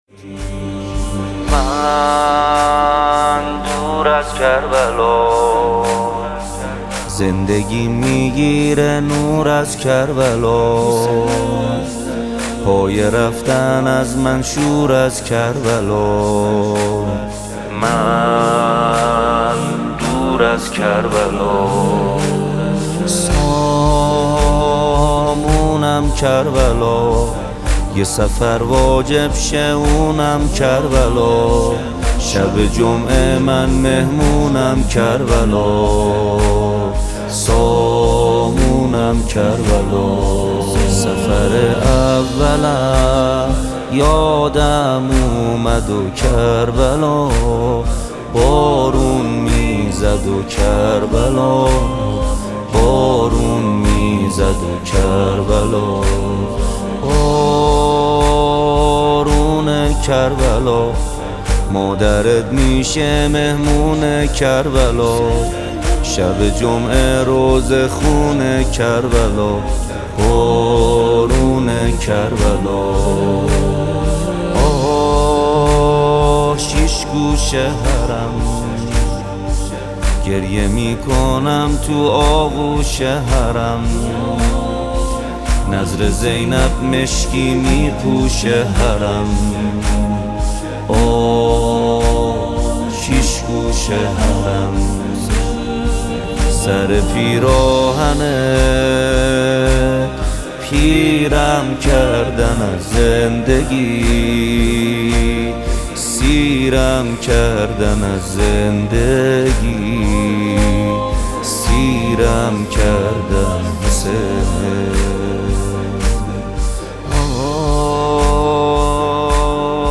دانلود نوحه با کیفیت 320 دانلود نوحه با کیفیت 128